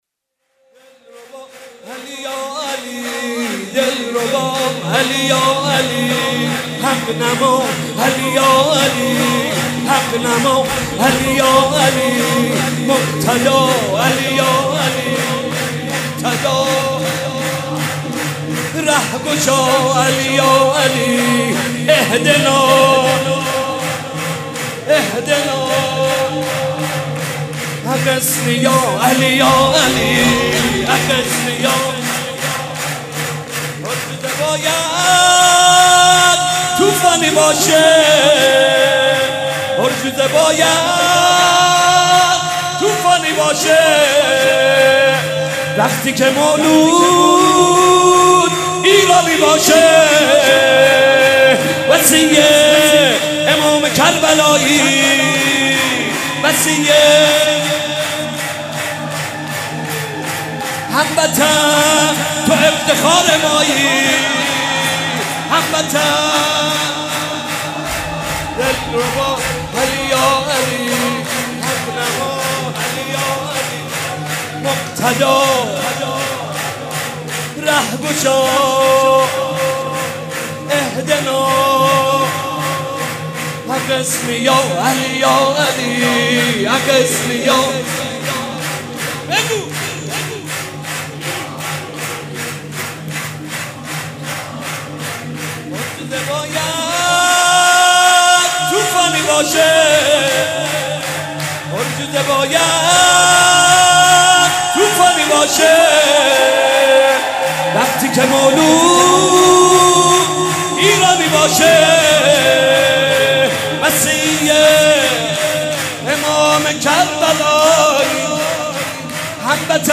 مناسبت : ولادت امام سجاد علیه‌السلام
قالب : شور